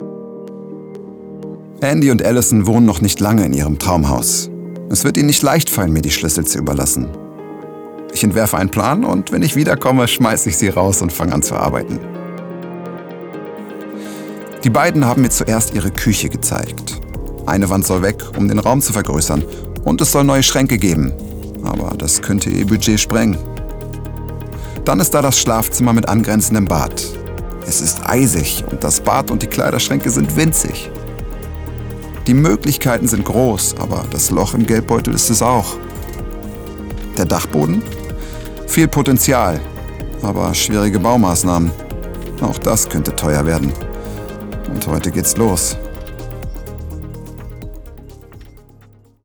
dunkel, sonor, souverän, plakativ
Mittel plus (35-65)
Voice Over 02 - Wohnen
Comment (Kommentar), Doku, Narrative, Off, Overlay